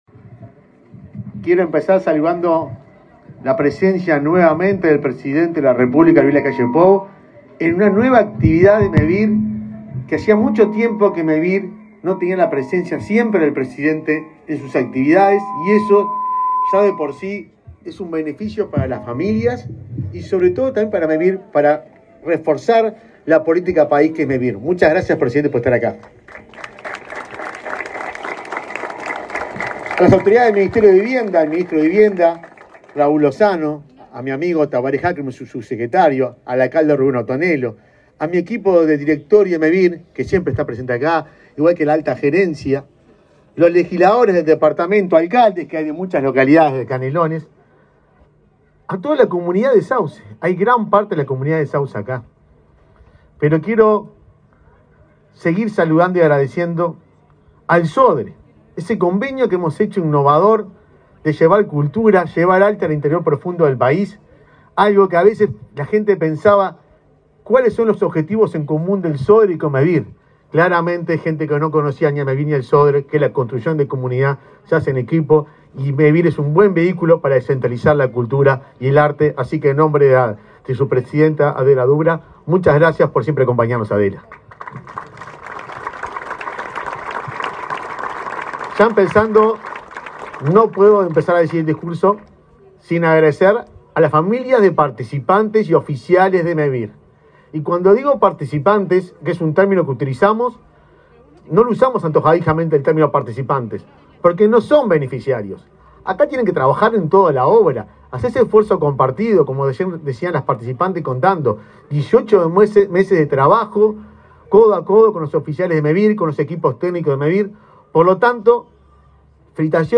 Palabras de autoridades en inauguración de Mevir
Palabras de autoridades en inauguración de Mevir 26/10/2023 Compartir Facebook X Copiar enlace WhatsApp LinkedIn El presidente de Mevir, Juan Pablo Delgado, y el ministro de Vivienda, Raúl Lozano, participaron de la inauguración de 89 casas en la localidad de Sauce, Canelones.